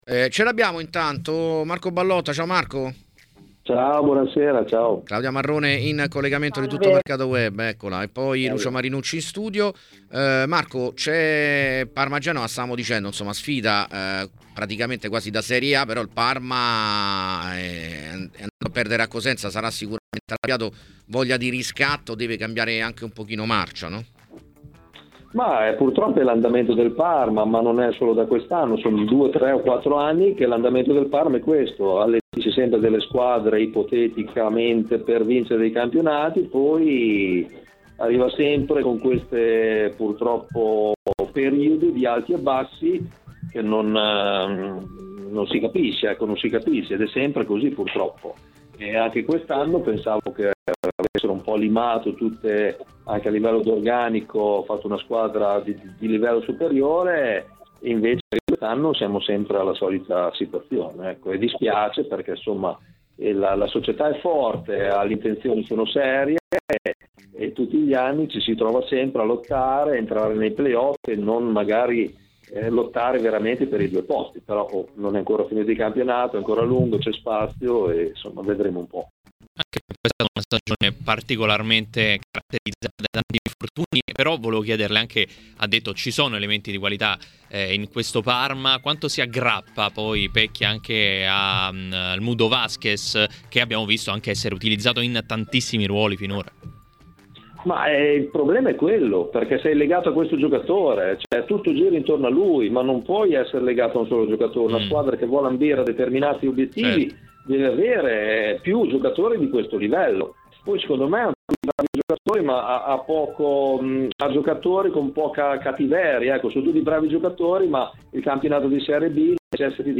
Durante il programma “Piazza Affari” a TMW Radio è intervenuto Marco Ballotta, allenatore ed ex calciatore, per commentare vari temi, tra cui il Parma e il suo andamento: “Il Parma purtroppo ha un andamento molto altalenante.